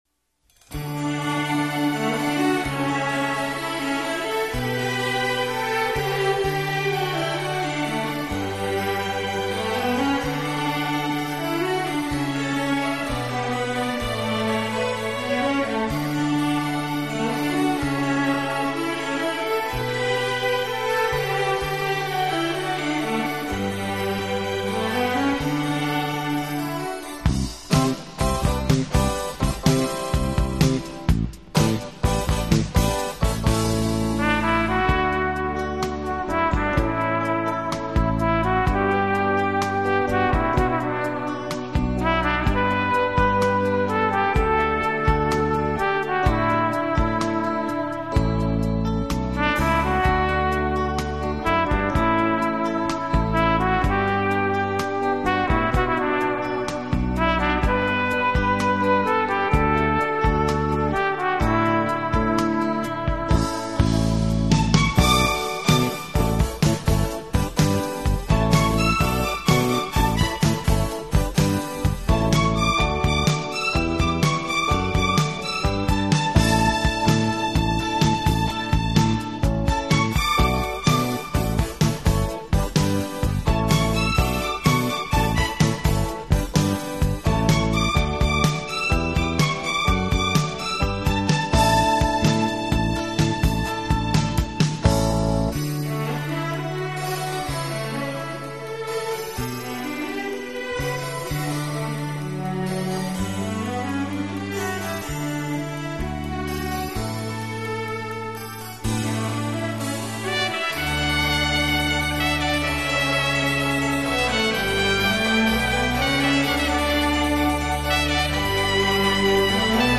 给朋友们送上一个迷人的情调音乐专辑，带领你我进入一个崭新浪漫和华 丽的音乐世界，音乐中曼妙的空间感便自然而然地完全展现！